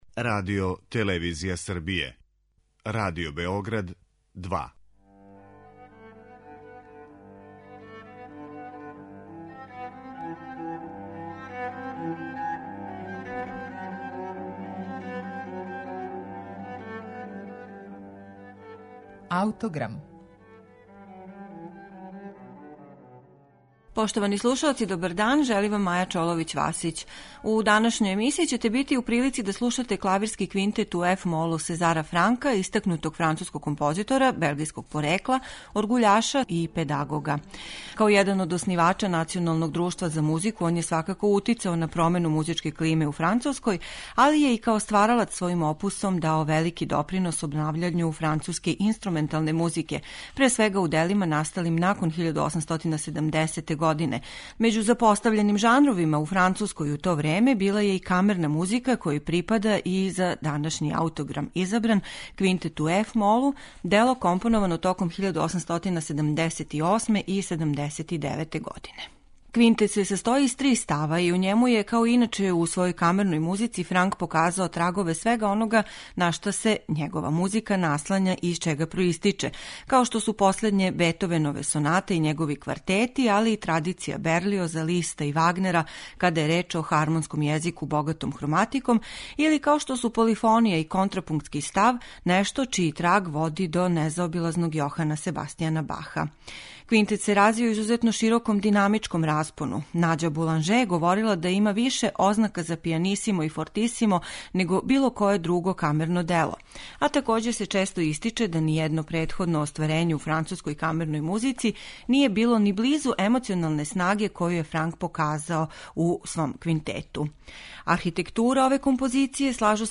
Данашња емисија је посвећена Клавирском квинтету у eф-молу Сезара Франка.
Важна карактеристика Франковог музичког стила - принцип мотивског повезивања ставова - присутна је и у Квинтету који ћете данас слушати у извођењу Свјатослава Рихтера и Гудачког квартета "Бородин".